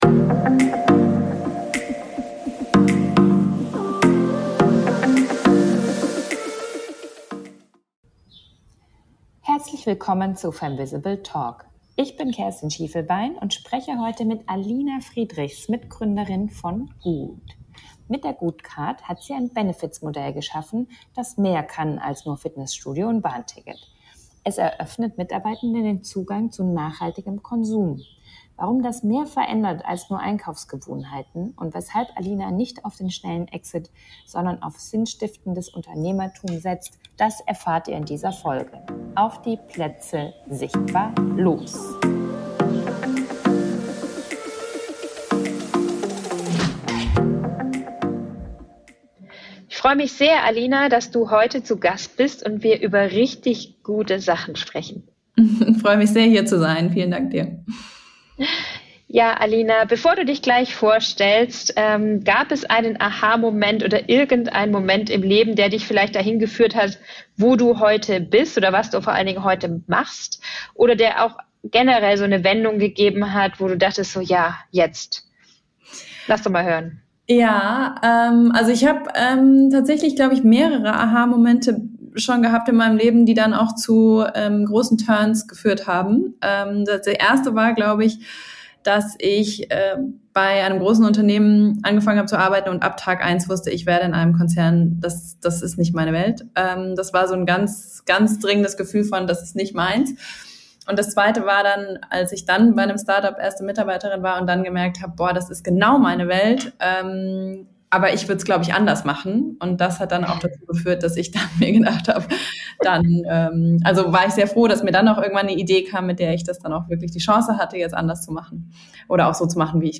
Ein Gespräch über nachhaltiges Unternehmertum und die Frage, wie Benefits zum Kulturwandel beitragen können.